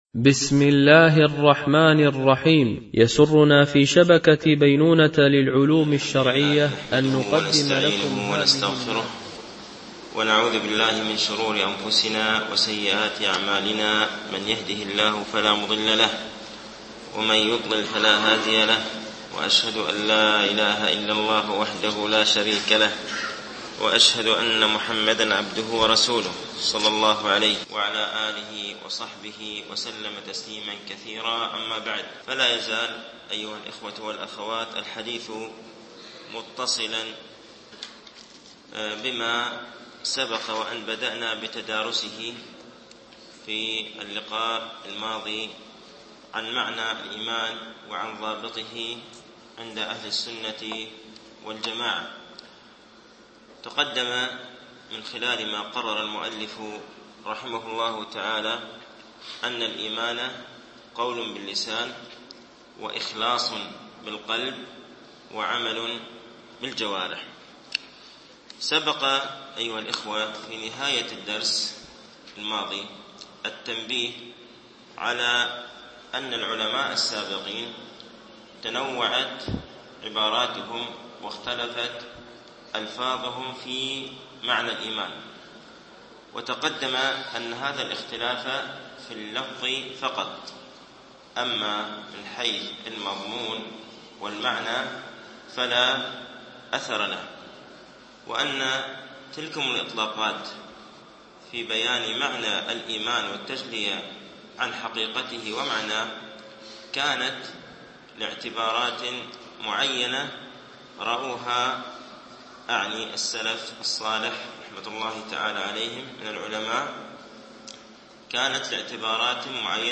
شرح مقدمة ابن أبي زيد القيرواني ـ الدرس السابع و الخمسون